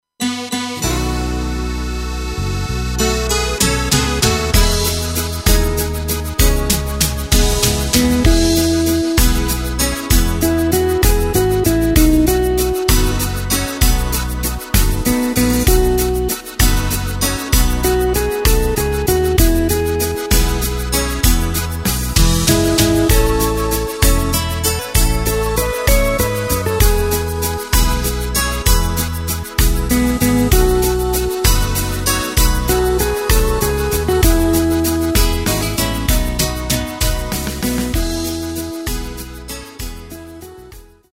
Takt:          3/4
Tempo:         194.00
Tonart:            E
Schönes MitSing Medley aus dem Jahr 2024!